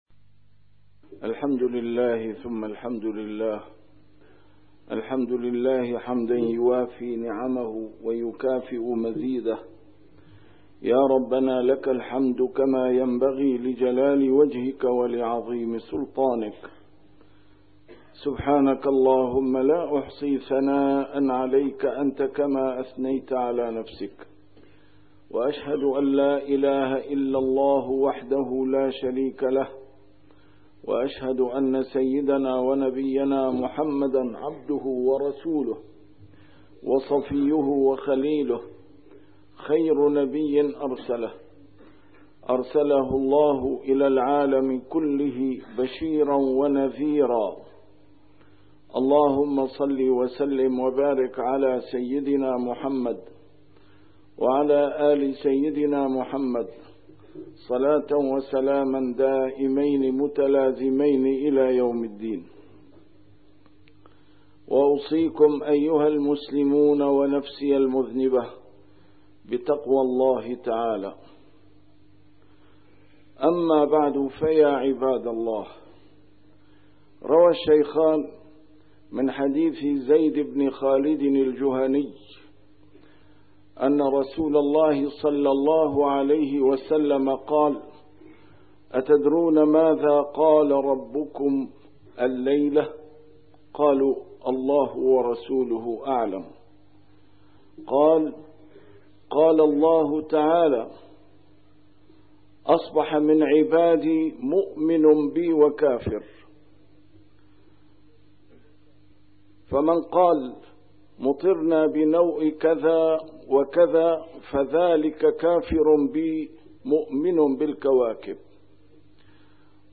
A MARTYR SCHOLAR: IMAM MUHAMMAD SAEED RAMADAN AL-BOUTI - الخطب - وهو الذي ينزل الغيث من بعد ما قنطوا